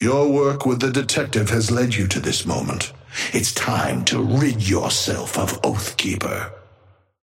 Amber Hand voice line - Your work with the detective has led you to this moment.
Patron_male_ally_ghost_oathkeeper_5b_start_01.mp3